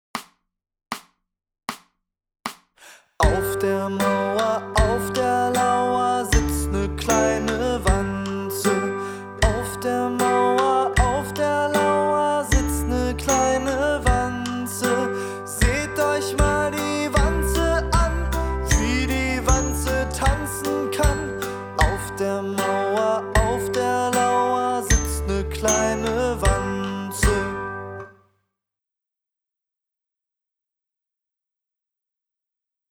Begleitung